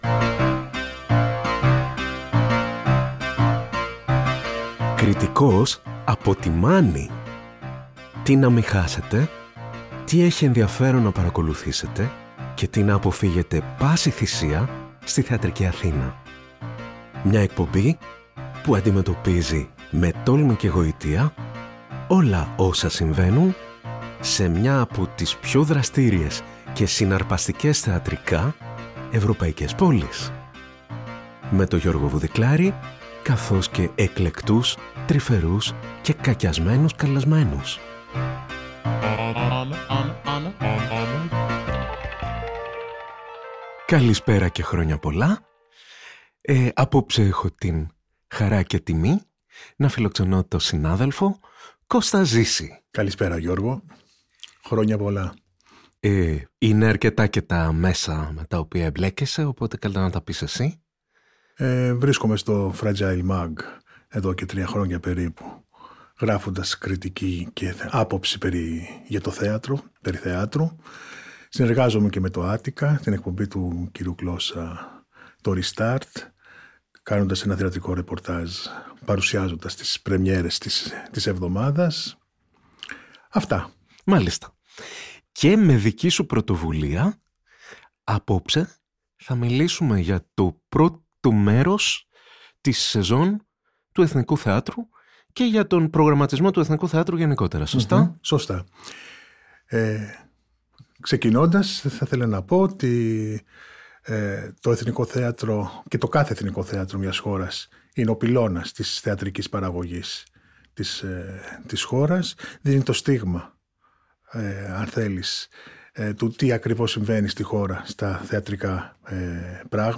Μια νέα εκπομπή που θα μάς συστήνει όλες τις θεατρικές παραστάσεις που αξίζει να γνωρίζουμε για τους σωστούς ή τους… λάθος λόγους! Συζητήσεις μεταξύ κριτικών που συμφωνούν ή διαφωνούν για το τι δεν πρέπει να χάσουμε, αλλά και το τι πρέπει να αποφύγουμε στη θεατρική Αθήνα.